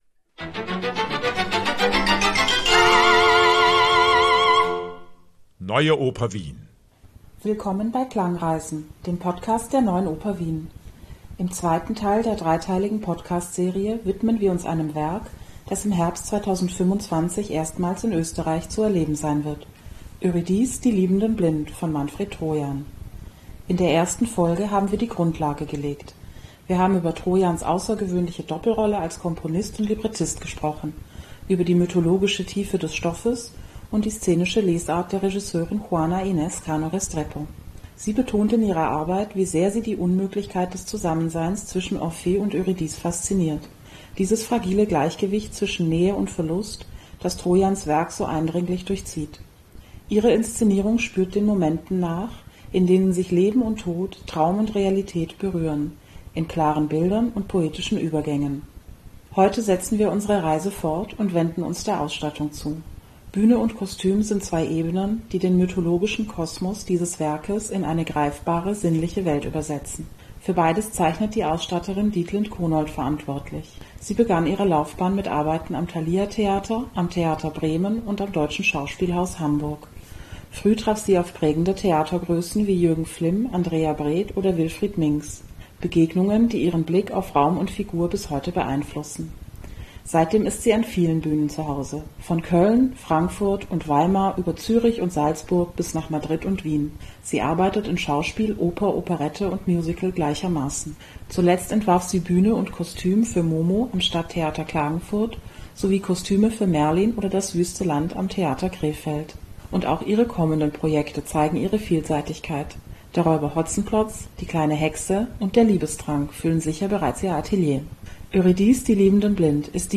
Die für diesen Podcast verwendeten Musikbeispiele wurden dankenswerterweise von den Archivaufnahmen der De Nationale Opera & Ballet Amsterdam zur Verfügung gestellt.